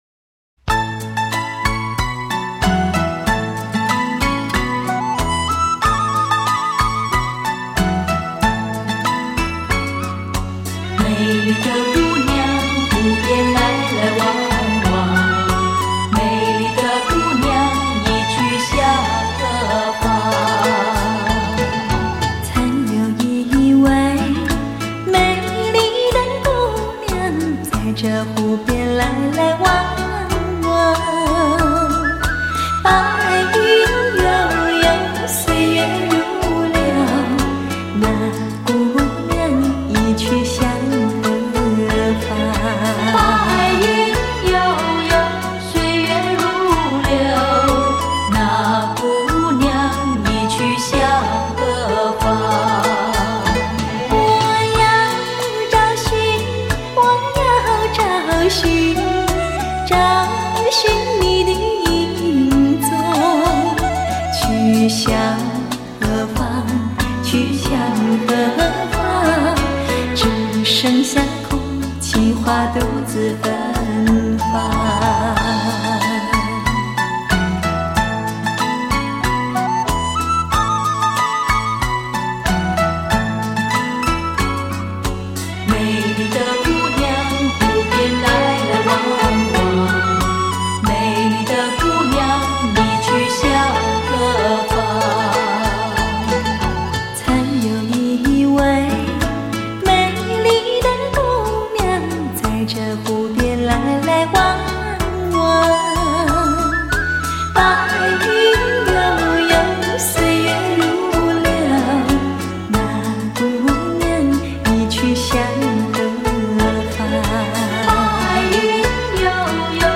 柔情新古典 非常心推荐